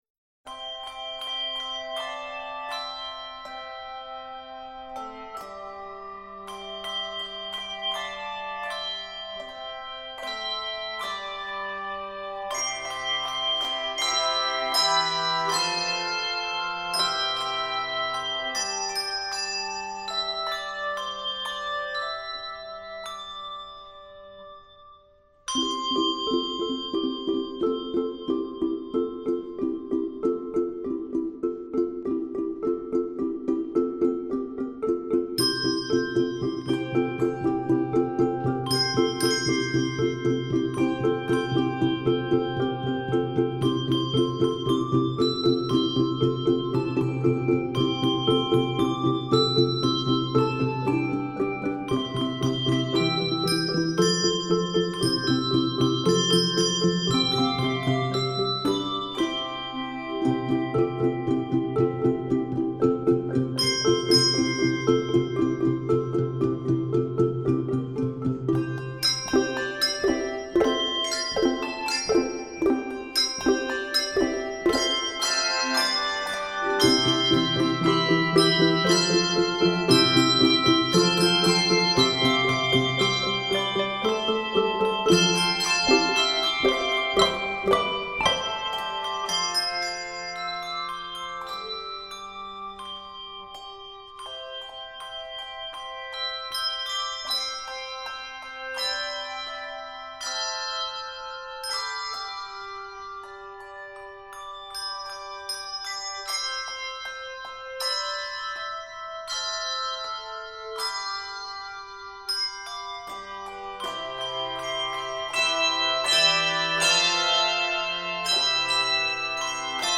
The beloved spiritual comes alive
Keys of C Major and D Major.